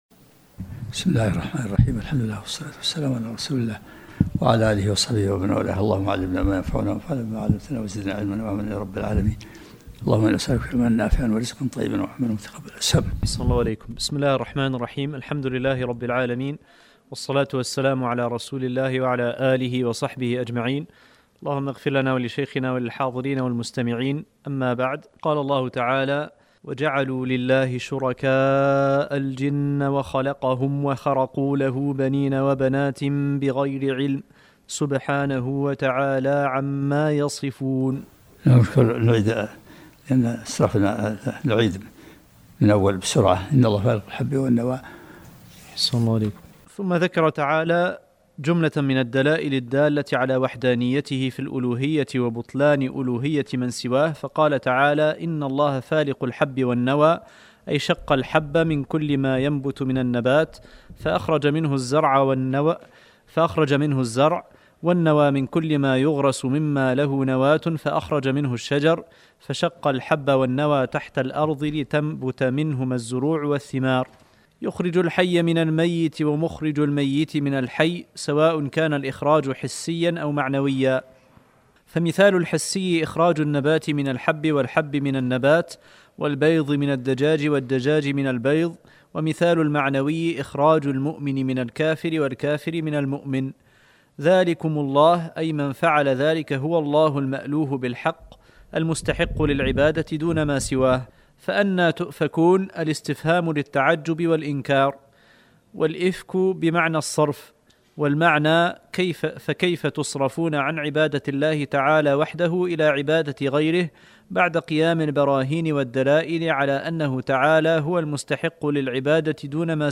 الدرس الحادي و العشرون من سورة الانعام